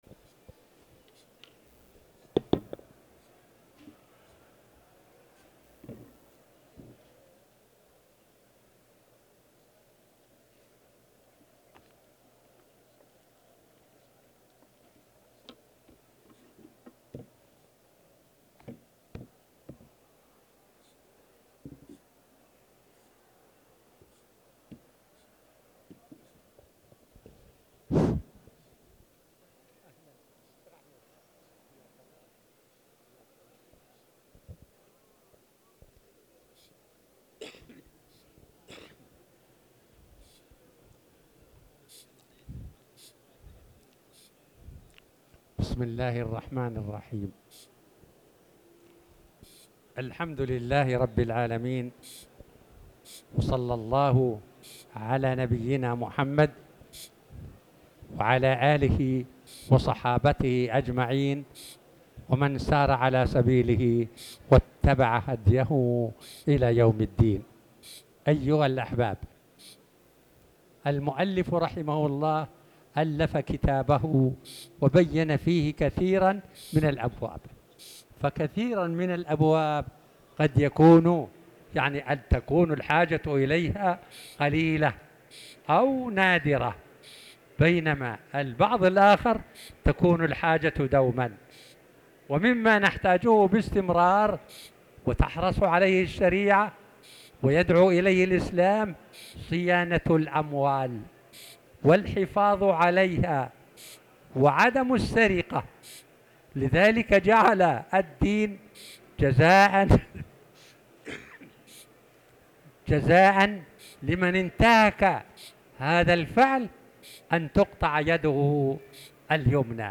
تاريخ النشر ٢٠ صفر ١٤٤٠ هـ المكان: المسجد الحرام الشيخ